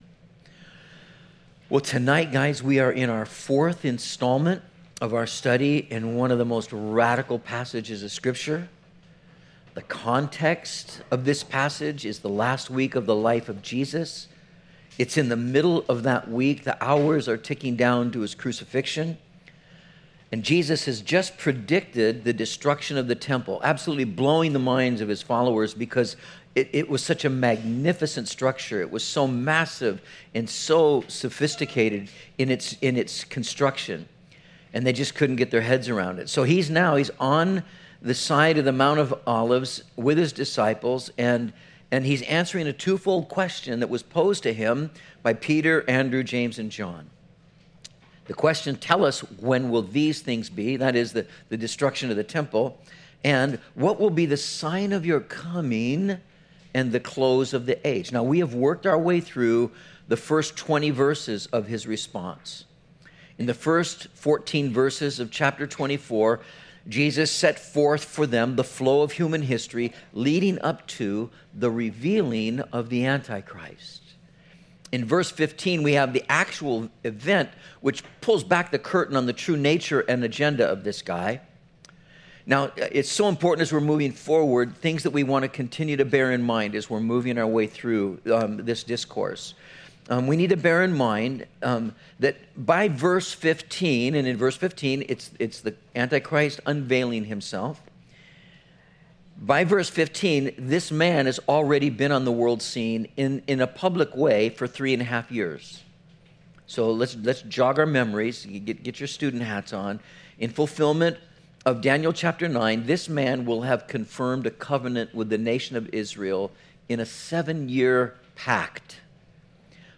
03/18/19 The Great Tribulation and the Return of Jesus - Metro Calvary Sermons